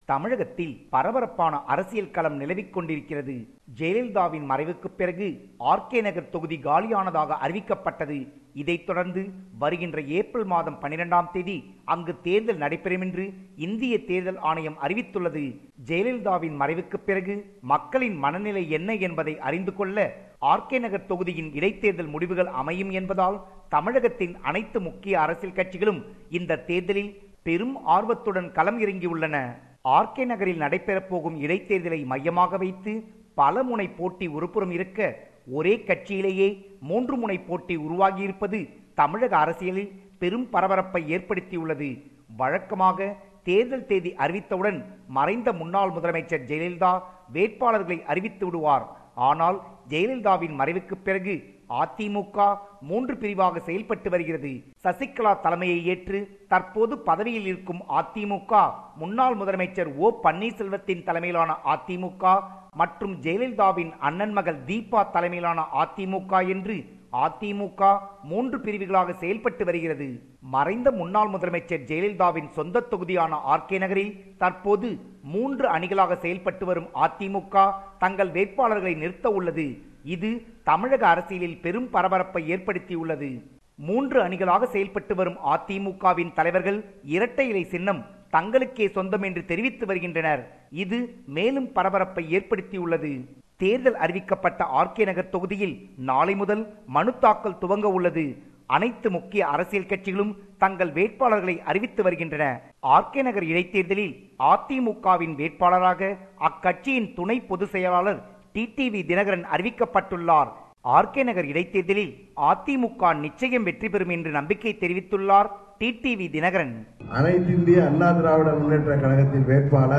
compiled a report focusing on major events/news in Tamil Nadu / India.